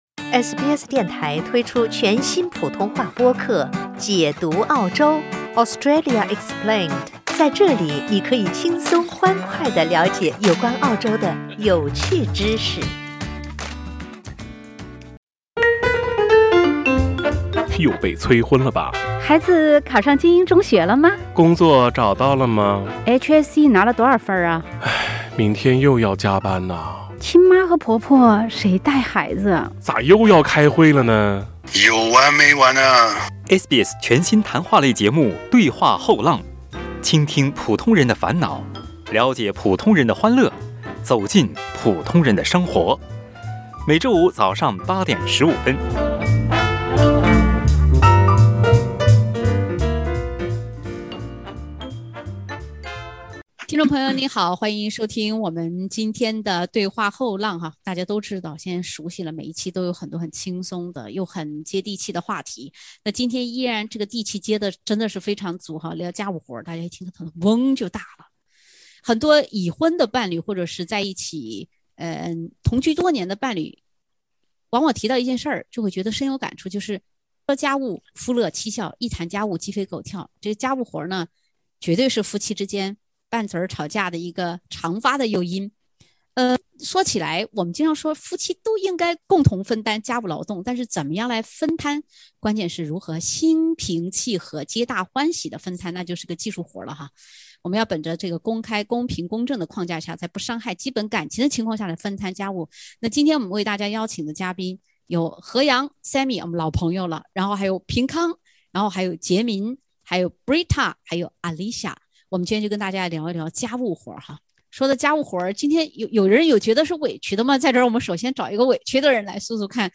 家务活儿，绝对是夫妻间吵架拌嘴的一个常发诱因。（点击封面图片，收听欢乐对话）